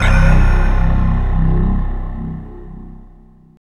SI2 SYNTH 06.wav